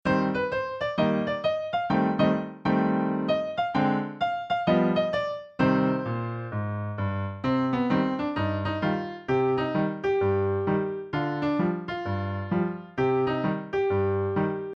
Folk Song Lyrics and Sound Clip